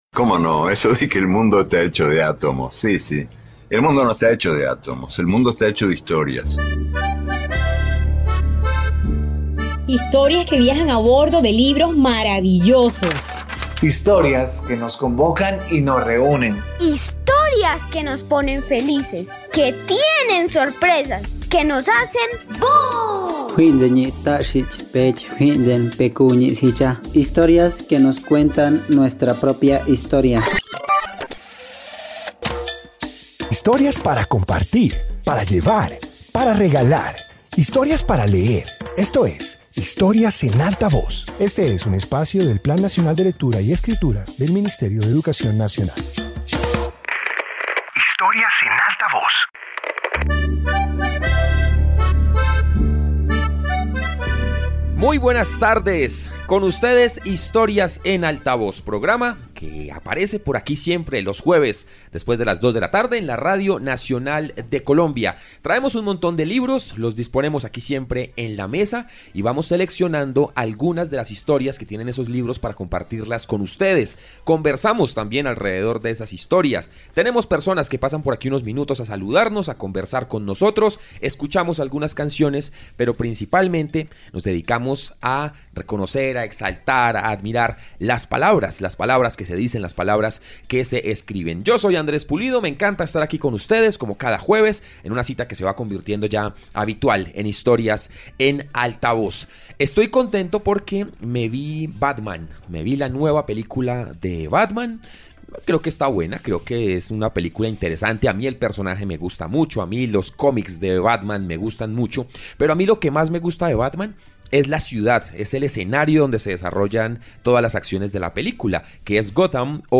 Introducción Este episodio de radio reúne historias ambientadas en escenarios imaginarios. Presenta lugares mágicos creados por la literatura y explora cómo estos territorios alimentan la creatividad y la fantasía.